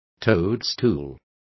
Also find out how hongo is pronounced correctly.